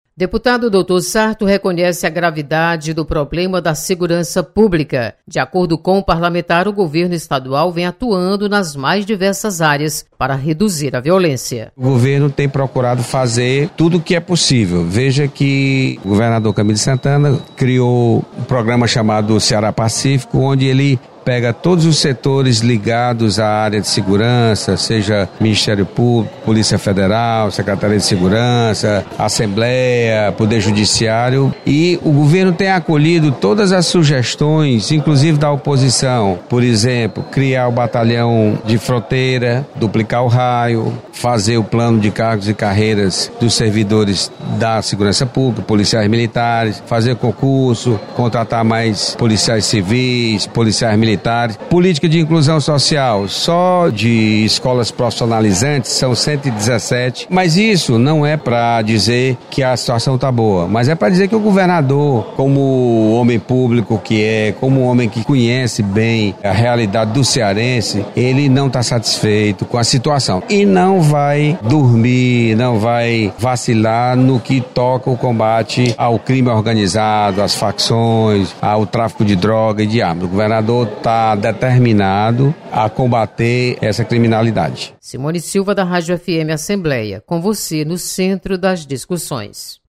Você está aqui: Início Comunicação Rádio FM Assembleia Notícias Segurança